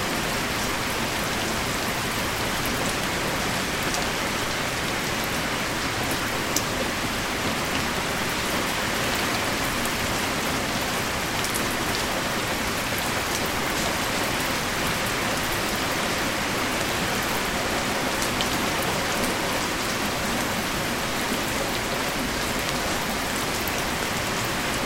enviro_rain_2.wav